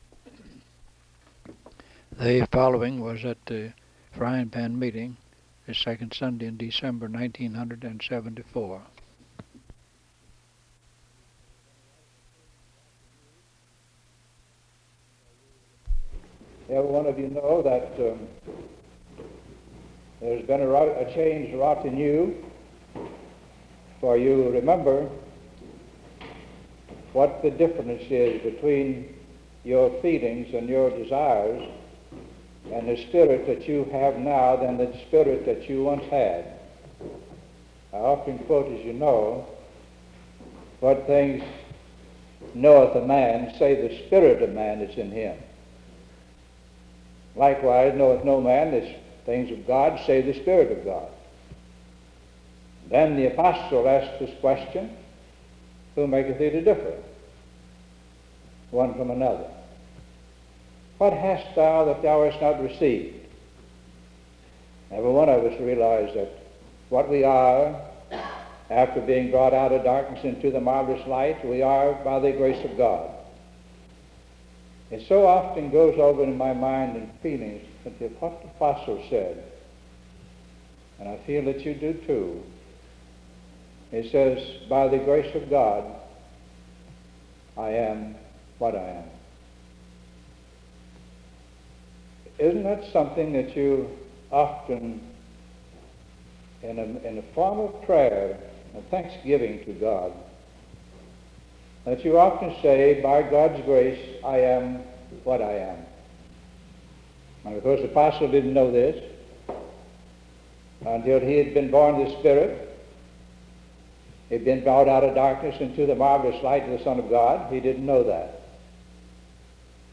Location Fairfax County (Va.) Herndon (Va.)
sermon